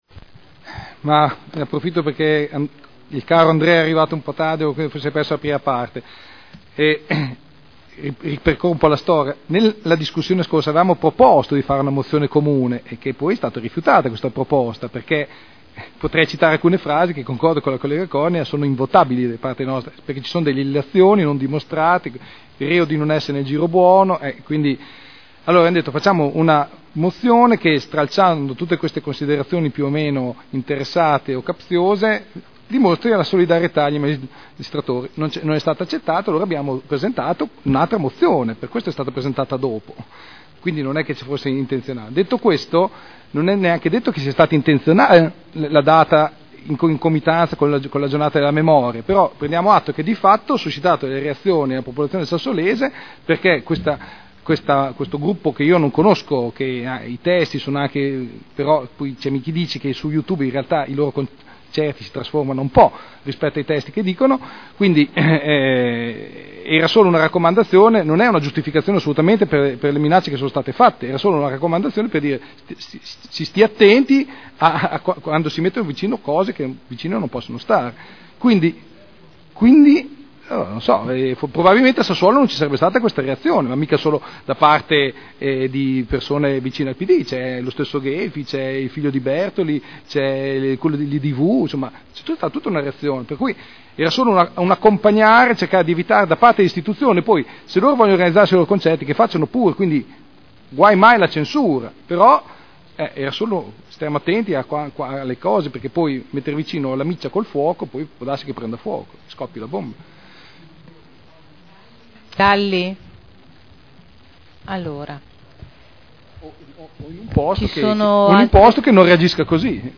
Enrico Artioli — Sito Audio Consiglio Comunale
Seduta del 12/04/2012. Dibattito sull' Ordine del giorno 13516 e Mozione 42048.